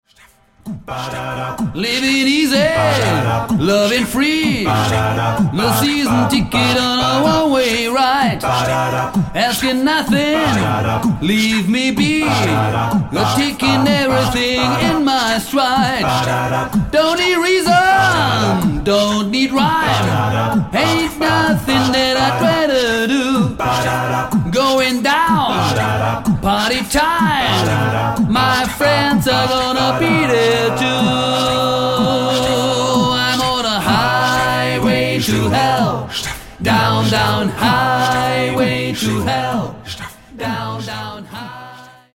Studio-Tonträger